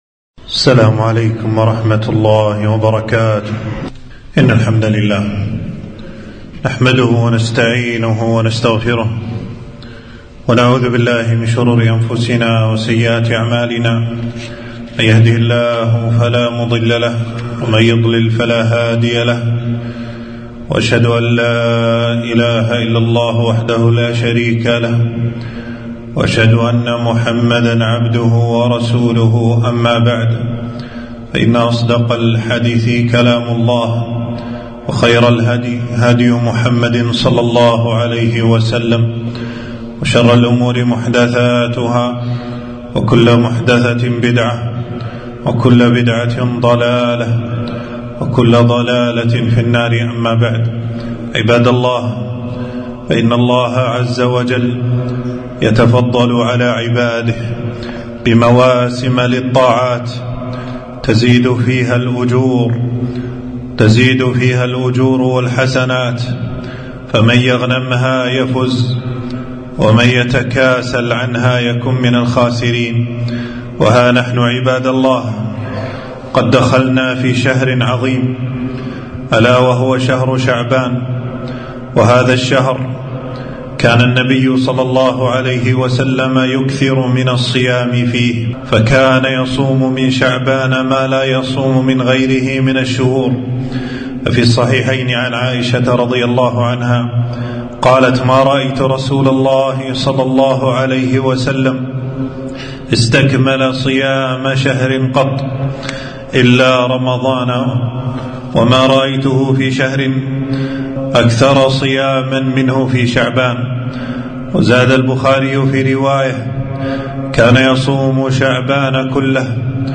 خطبة - شهر شعبان شهر الصيام والقرآن